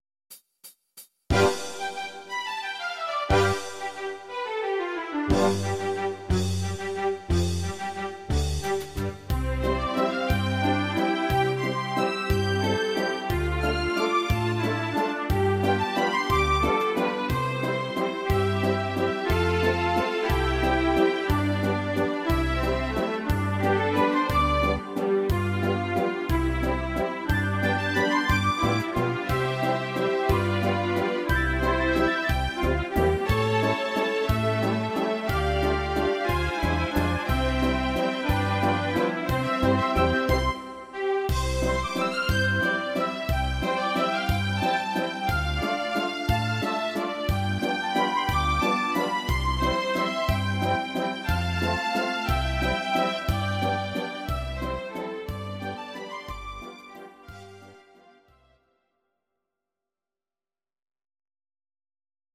instr. Strings